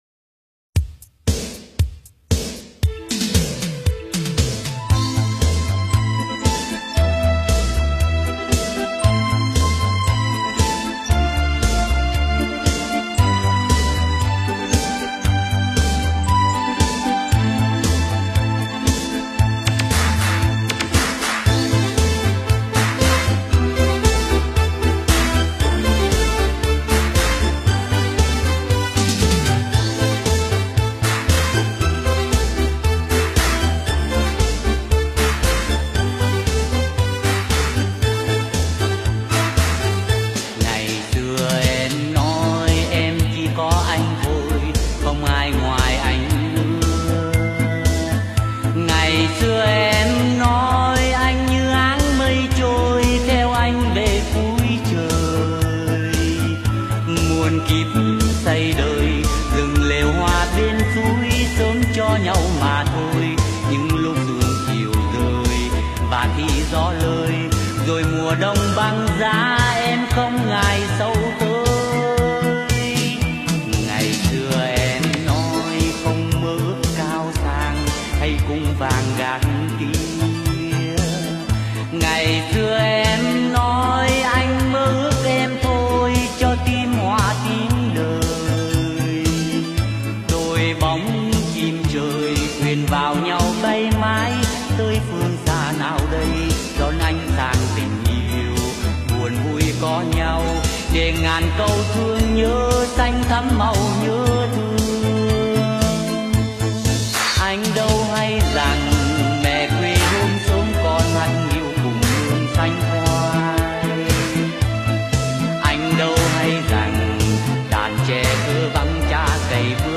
Nhạc Bolero Trữ Tình